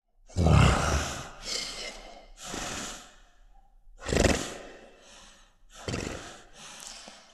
Dinosaur Snoring Sound
horror